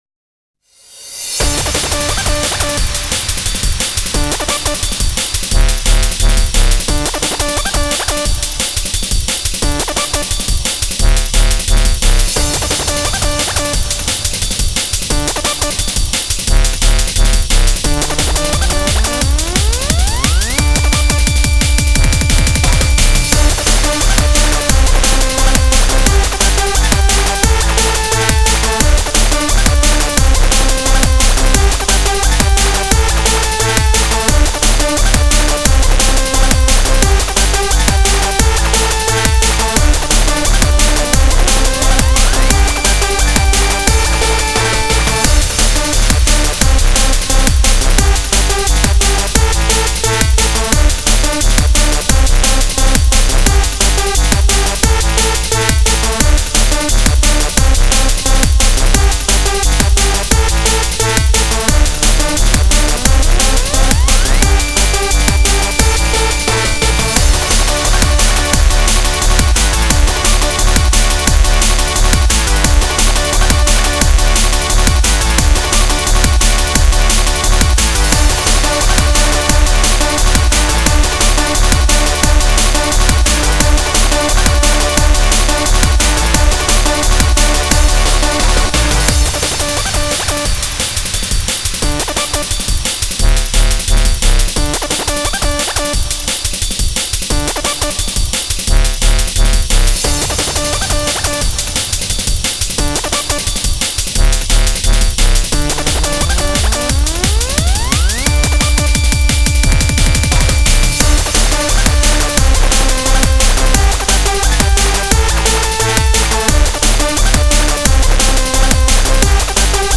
goofy jump up with some rave influences- not amazing but again i would rather have it here than rotting on my hard drive.
roll this shit [silly jump up dnb]
175bpm electronic edm breaks dnb drumnbass drumandbass rave